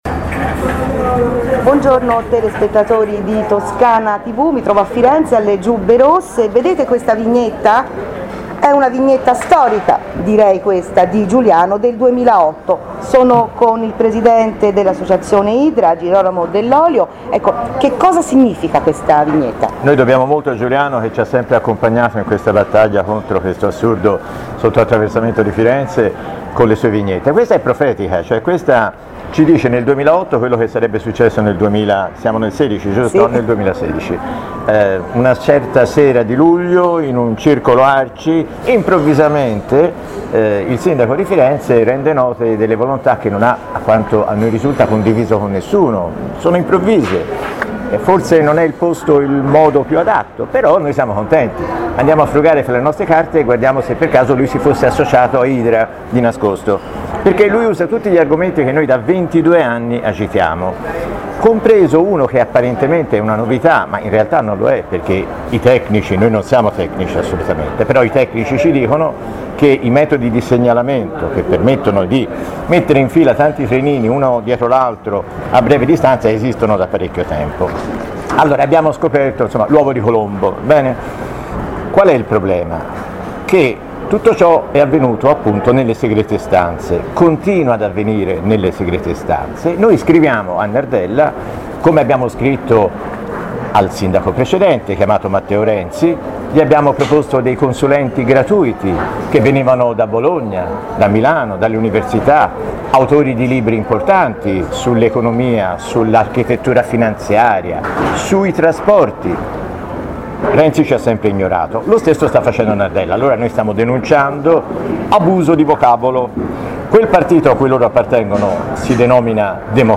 Intervista a Toscana TV (audio)
6.10.16-Conf.-stampa-Idra-Giubbe-Rosse-Intervista-a-Toscana-TV.mp3